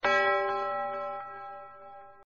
HitChime.mp3